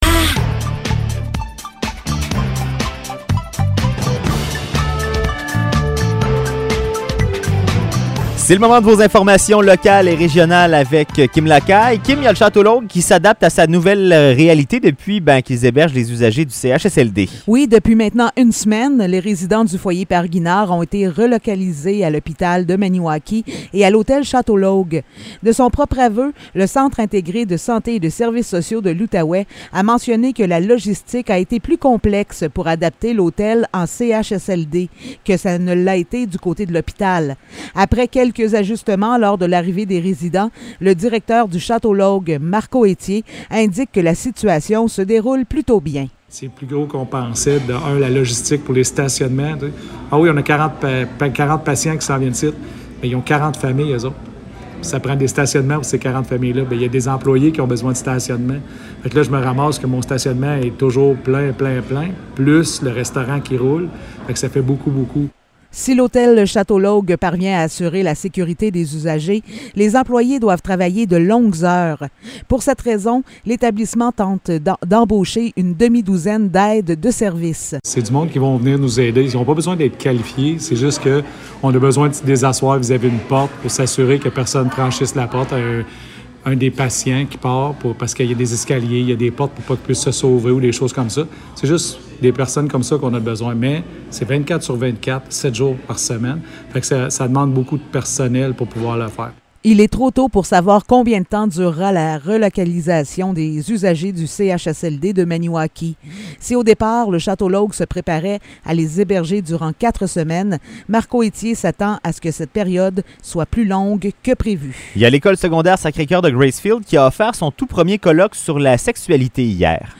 Nouvelles locales - 27 mai 2022 - 16 h